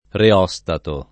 [ re 0S tato ]